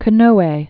(kōnōyĕ), Prince Fumimaro 1891-1945.